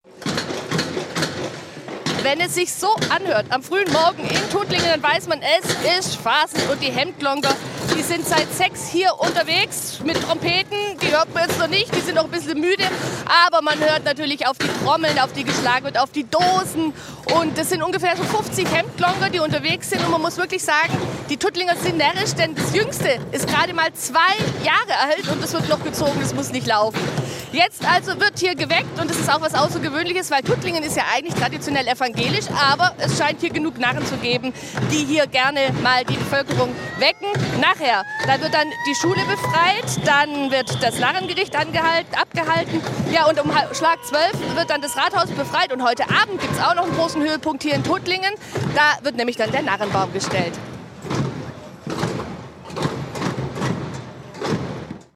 Lärm und Rüschenhosen in der Tuttlinger Stadt
Sie sehen zwar harmlos aus in ihren Nachthemden, aber der Lärm mit Glocken, Schellen und Topfdeckeln hat es in sich.
Der Narren-Nachwuchs darf auf der Schulter sitzen, während die anderen Narren trommeln.